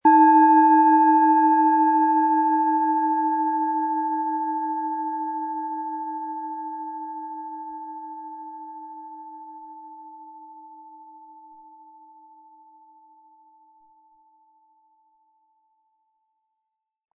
Planetenschale® Leidenschaftlich sein & Spielerische Energie fühlen mit Eros, Ø 10,7 cm, 100-180 Gramm inkl. Klöppel
Planetenton 1
Spielen Sie die Schale mit dem kostenfrei beigelegten Klöppel sanft an und sie wird wohltuend erklingen.